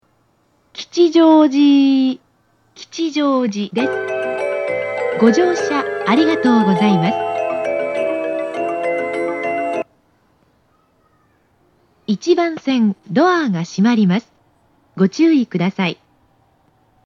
発車メロディー
0.7コーラスです。